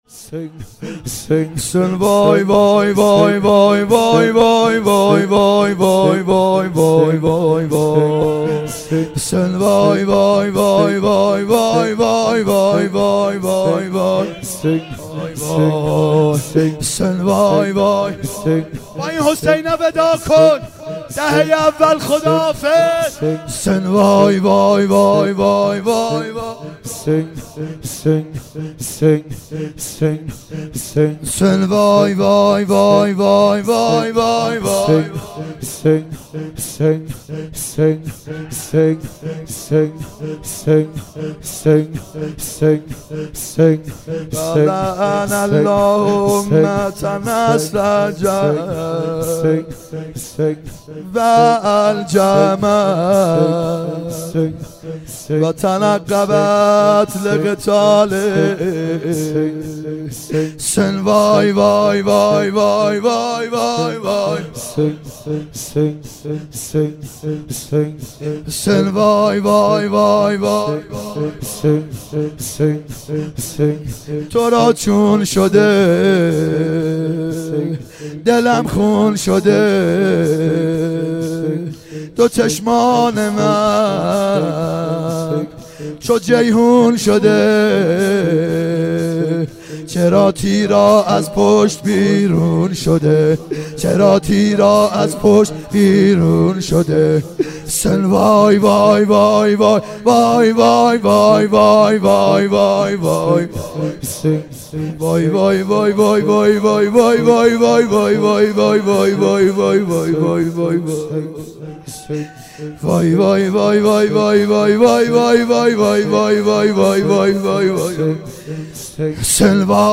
محرم 90 ( هیأت یامهدی عج)